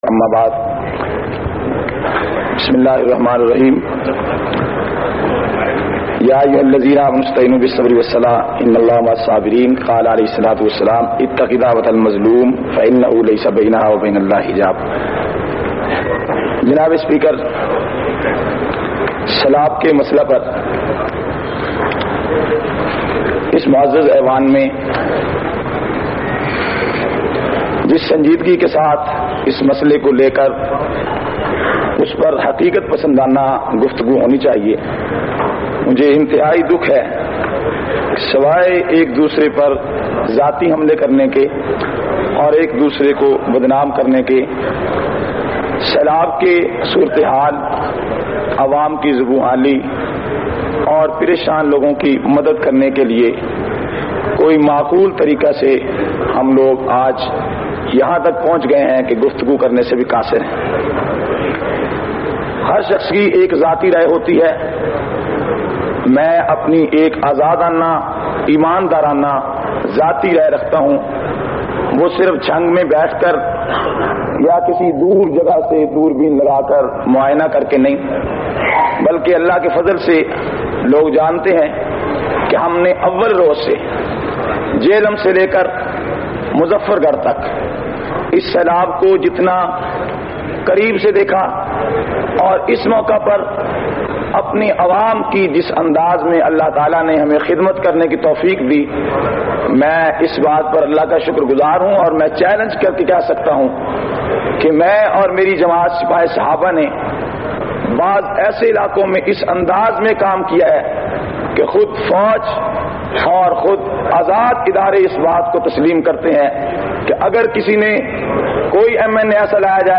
448- Qaomi Assembly Khutbat Vol 2.mp3